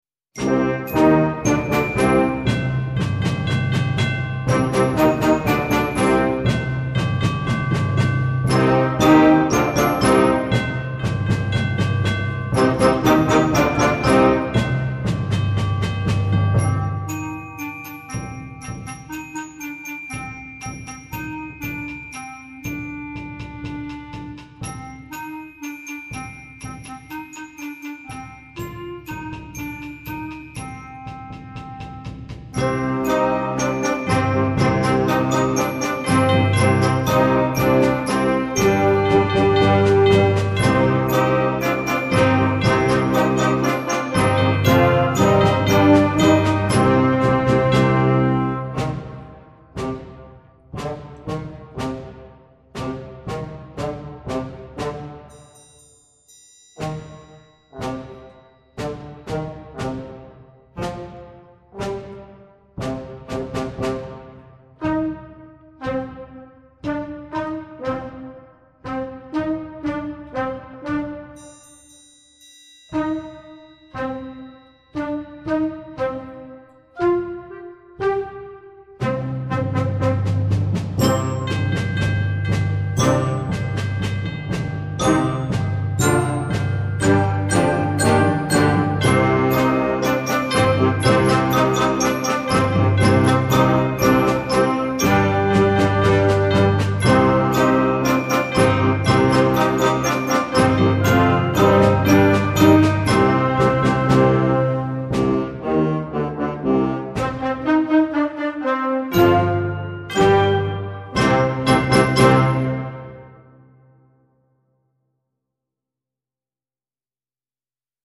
Concert Band
an edgy and exciting piece for very young band